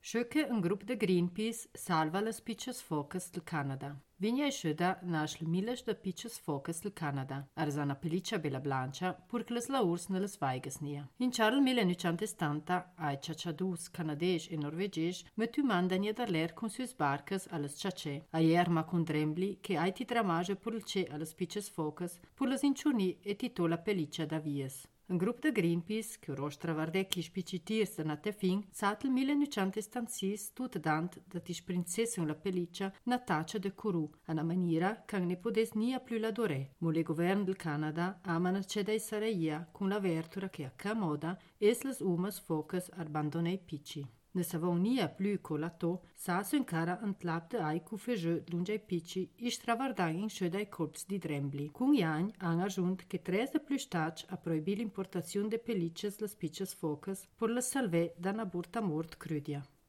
Ladino badiota